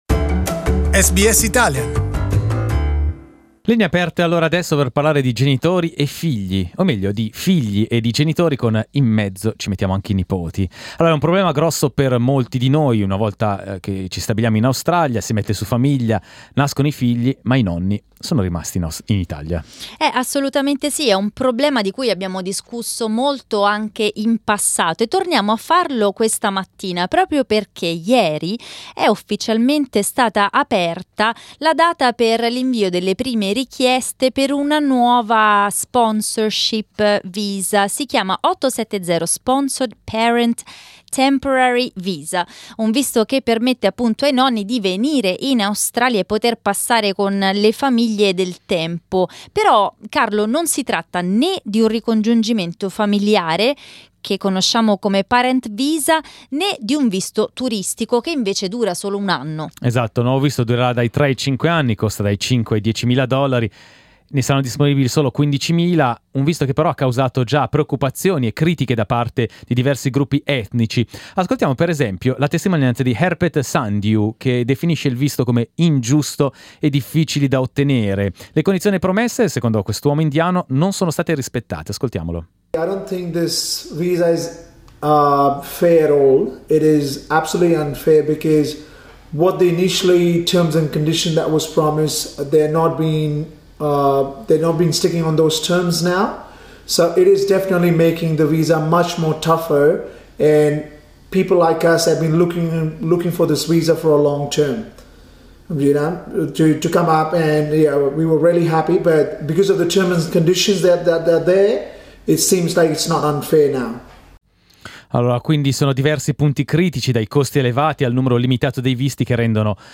We asked our audience what they thought of the new visa and its conditions. Click on the audio player to listen to the talkback.